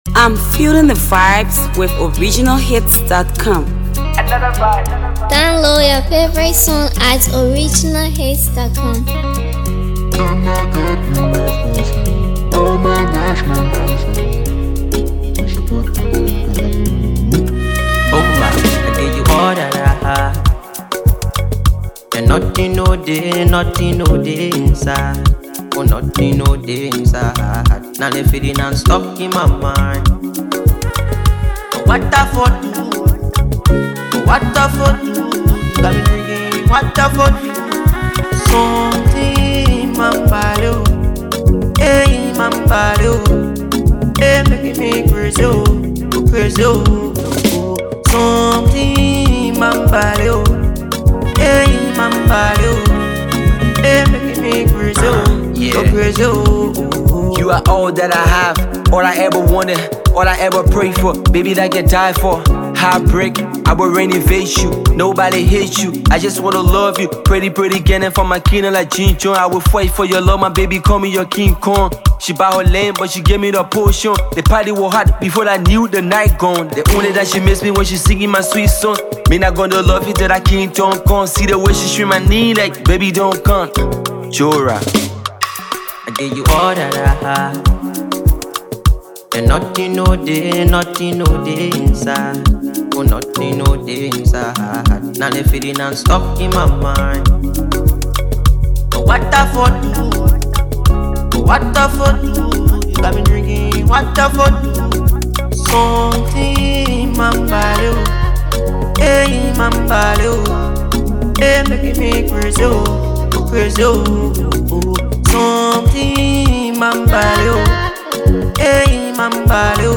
playlist banger